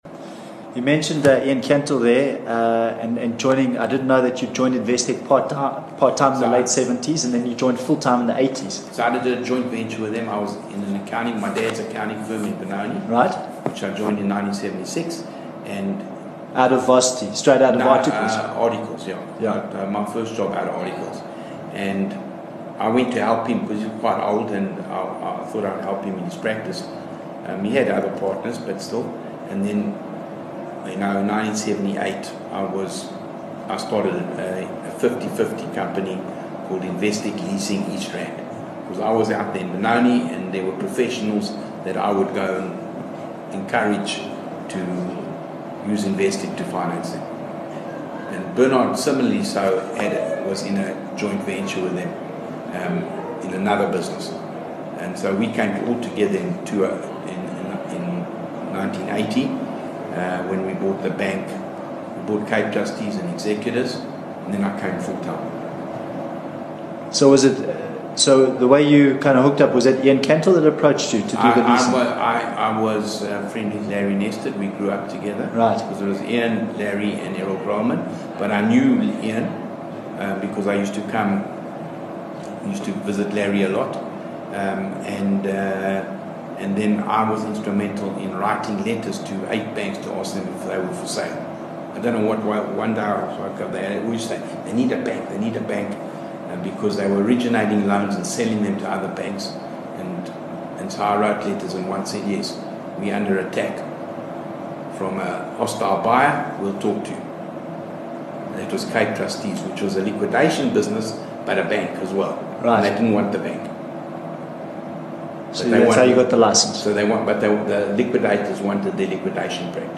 10 Dec Stephen Koseff interview - Part 4